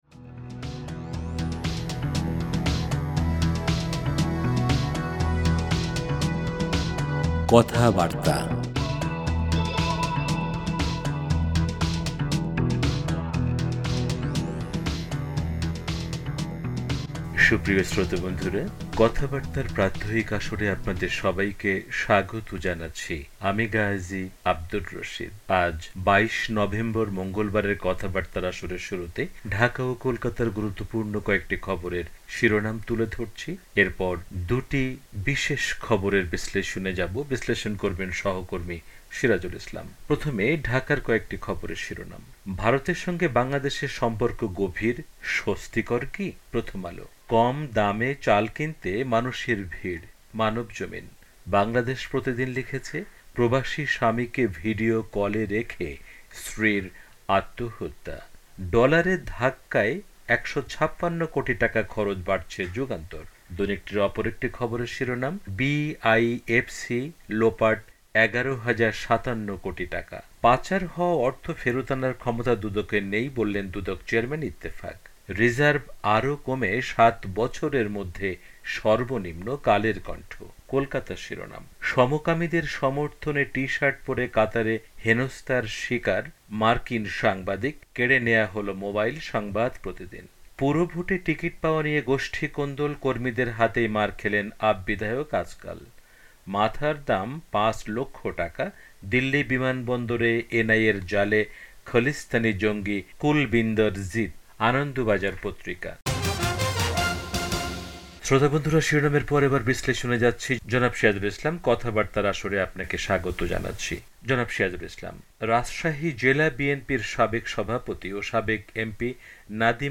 ঢাকার কয়েকটি খবরের শিরোনাম